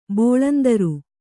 ♪ bōḷandaru